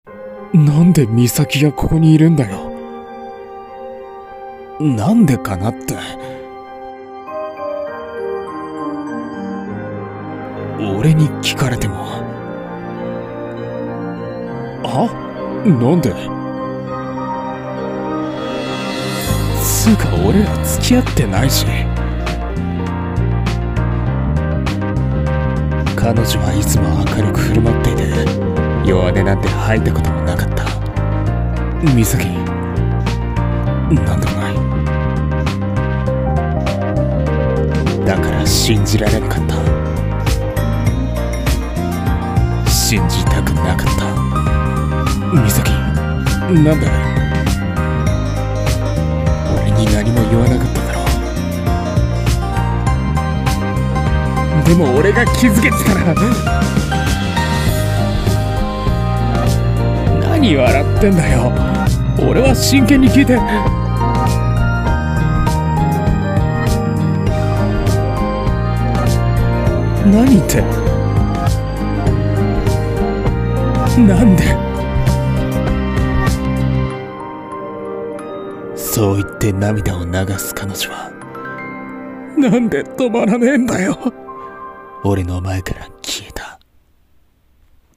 【二人声劇】